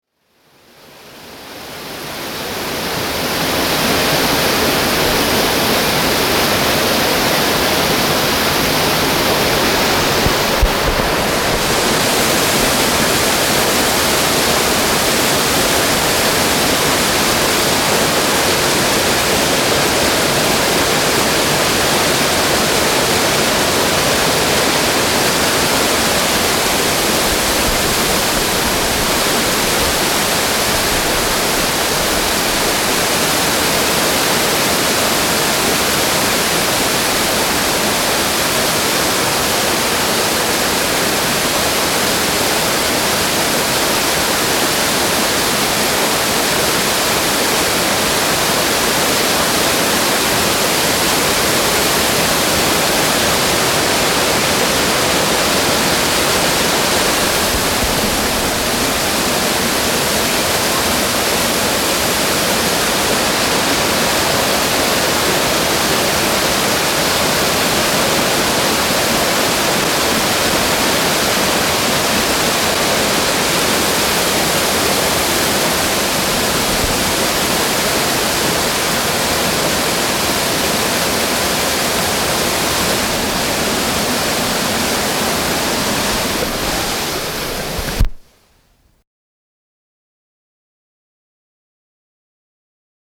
明記がない限りいずれも収録時間中はノーカット・手動録音レベル調整*なので、私が聴いた音をほぼそのまま聴くことができると思います。
TC-D5M（カセット）
ECM-MS957
写真サークルで訪れた養老の滝。写真だけでなく音も収めようと思い、「カセットデンスケ」を携えて滝の近くまで行ってみました。
大きなデンスケを肩から下げて滝の流れにマイクを向ける人は管理人の他におらず、観光客からは奇異の目で見られ、サークルのメンバーにも笑われました。